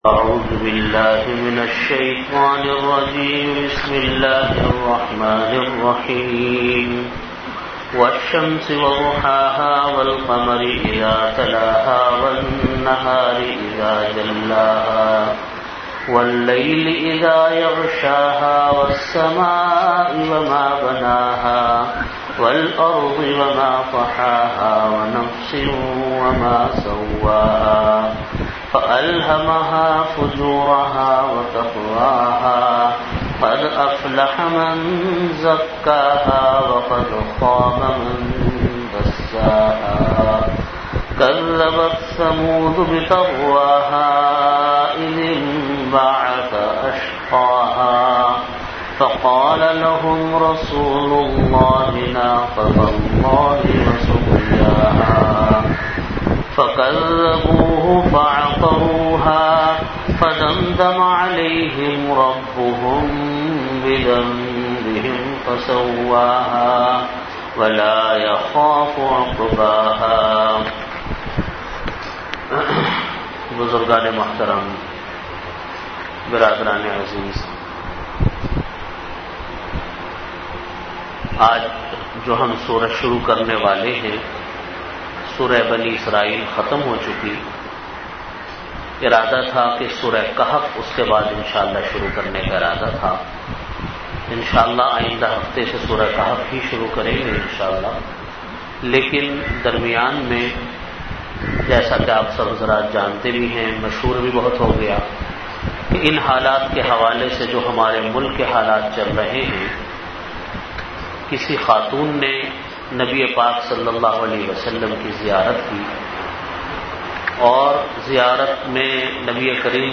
Bayanat · Jamia Masjid Bait-ul-Mukkaram, Karachi
Event / Time After Isha Prayer